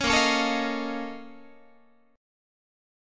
BbmM11 Chord